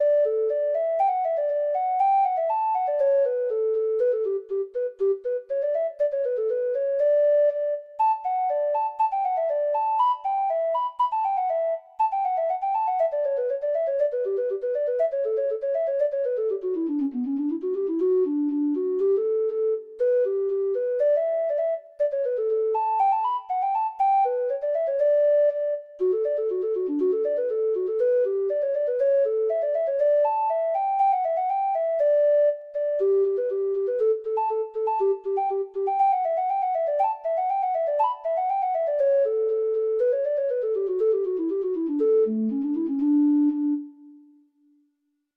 Traditional Music of unknown author.